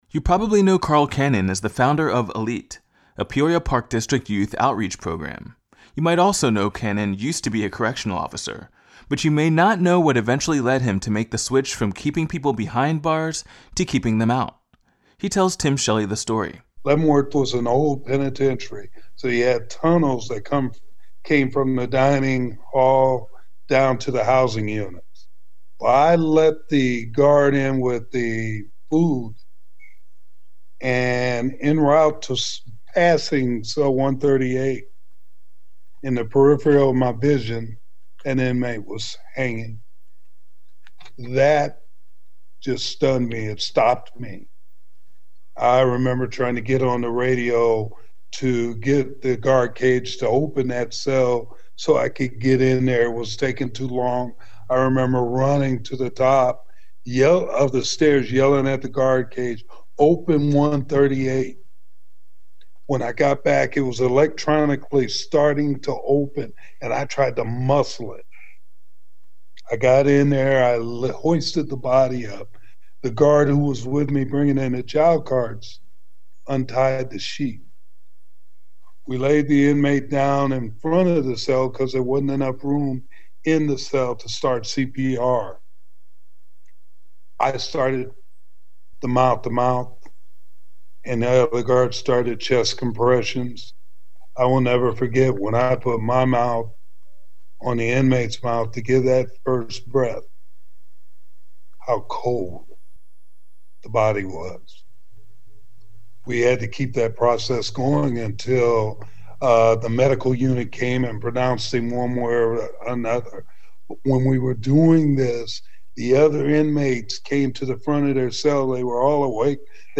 This interview was edited for clarity and length.